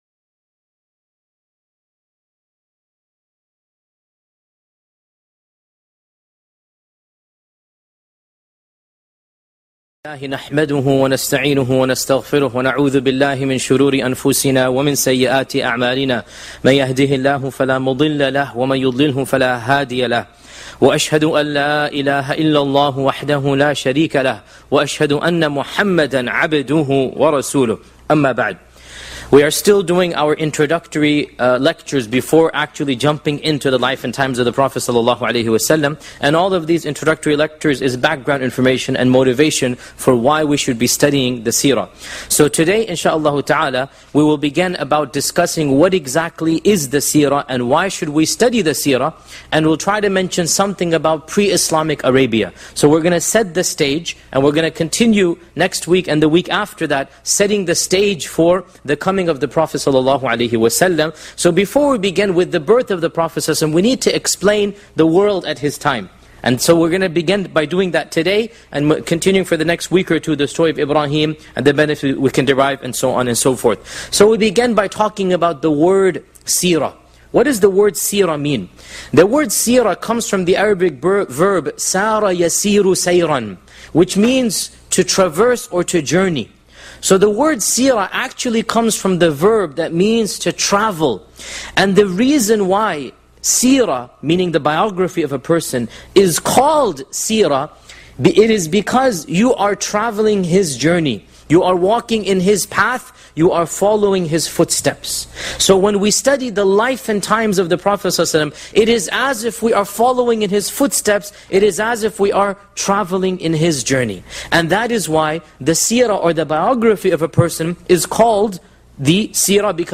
This lecture was recorded on 11th May, 2011 Shaykh Yasir Qadhi gives a detailed analysis of the life of Prophet Muhammad (peace be upon him) from the original sources.